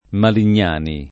[ malin’n’ # ni ]